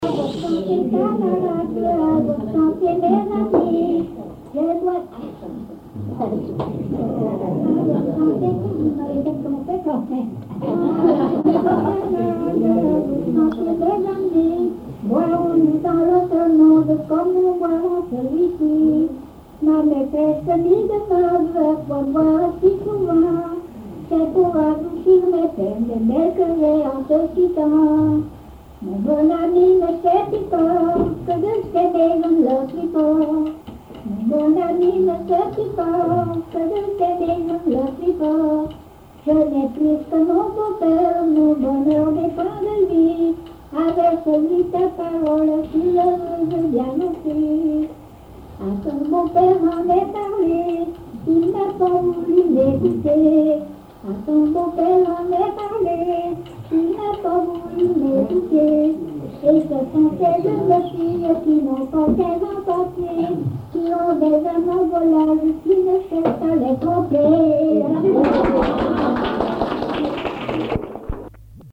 Localisation Cancale (Plus d'informations sur Wikipedia)
Usage d'après l'analyste circonstance : bachique ;
Catégorie Pièce musicale inédite